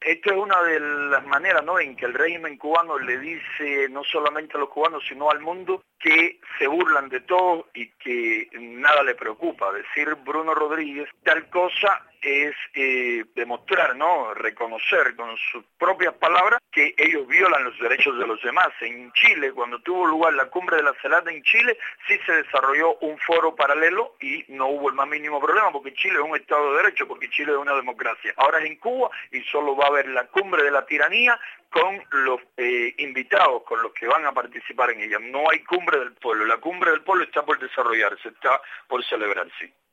“Fuimos secuestrados” afirmó el secretario ejecutivo de la Unión Patriótica de Cuba UNPACU, Jose Daniel Ferrer en conversación telefónica a Radio Martí después de ser liberado la mañana del domingo.